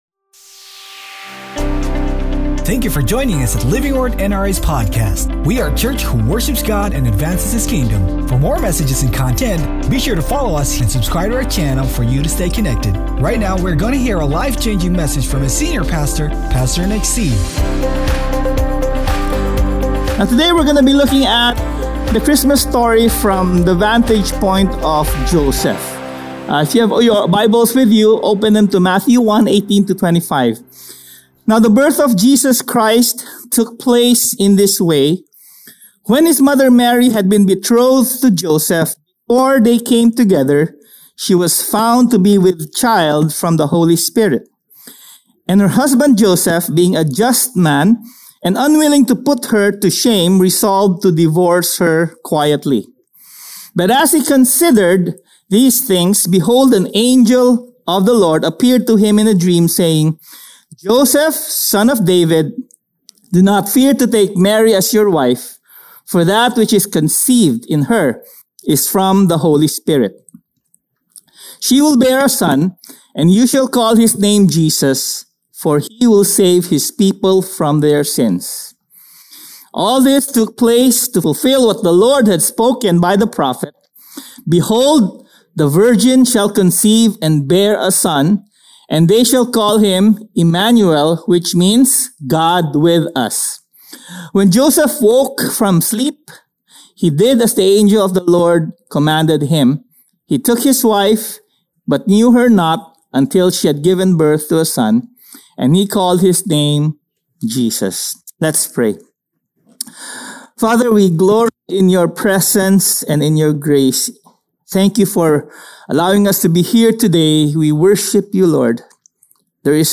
Sermon Title: JOSEPH’S SURPRISE Scripture Text: MATTHEW 1:18-25 Sermon Notes: MATTHEW 1:18-25 ESV 18 Now the birth of Jesus Christ took place in this way.